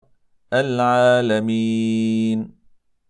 a ـــ Att inte ge den sin rätta ghunnah när man stannar på den, som i:
﴾ٱلۡعَٰلَمِيـنَ﴿